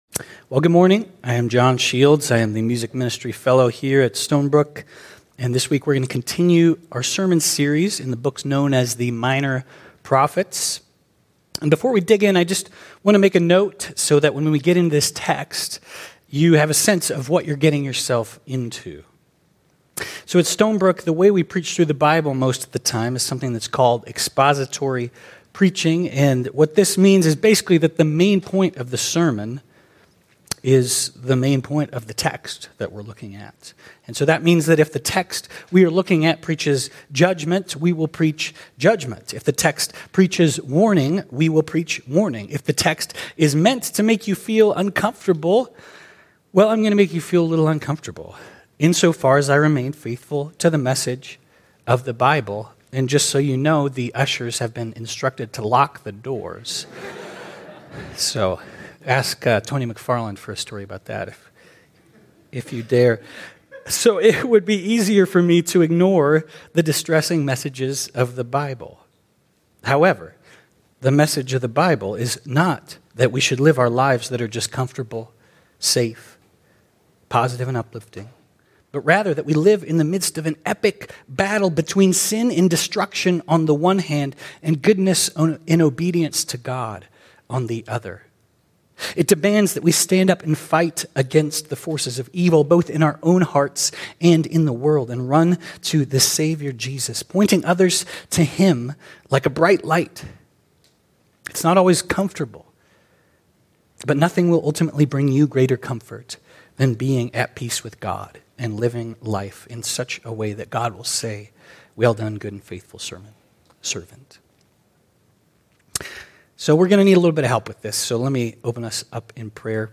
This week we will continue our sermon series in the books known as the Minor Prophets.